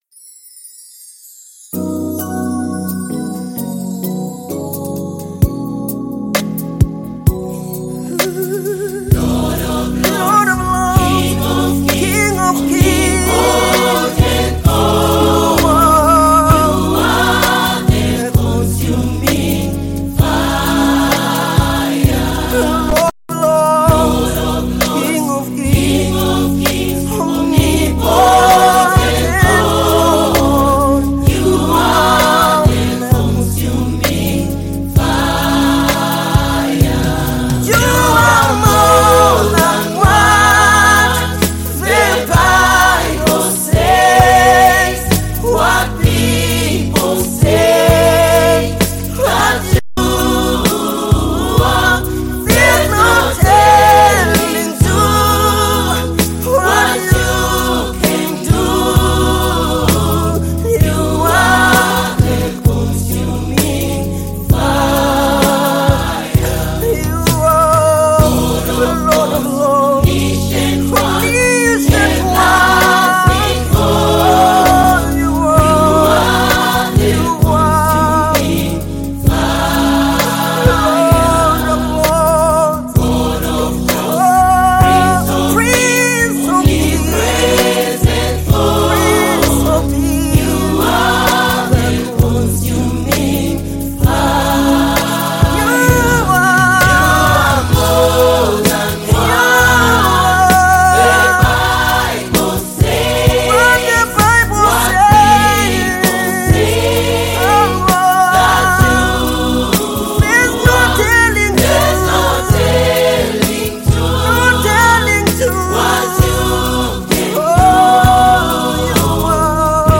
gospel
powerful and spirit-filled song
The song’s rich instrumentation